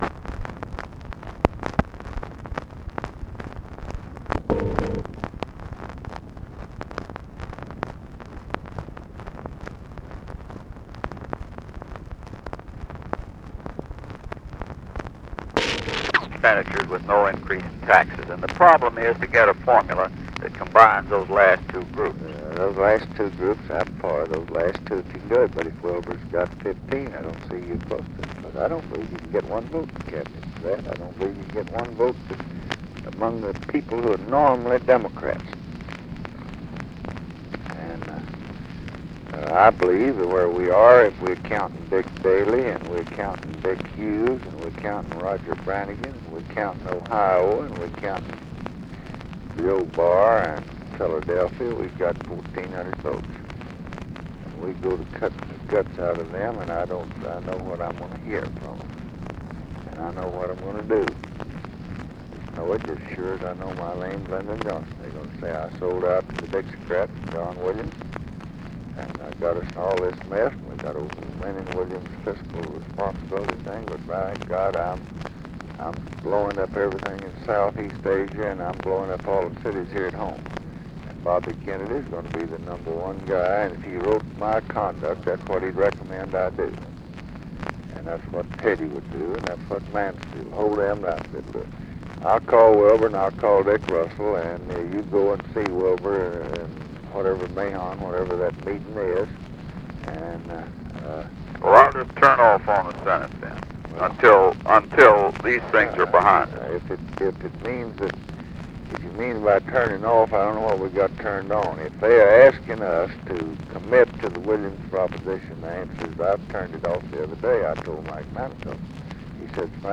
Conversation with HENRY FOWLER, March 24, 1968
Secret White House Tapes